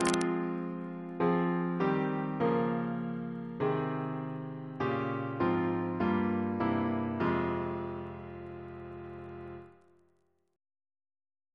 Single chant in F minor Composer: Sir John Goss (1800-1880), Composer to the Chapel Royal, Organist of St. Paul's Cathedral Reference psalters: ACP: 11